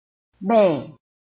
臺灣客語拼音學習網-客語聽讀拼-詔安腔-單韻母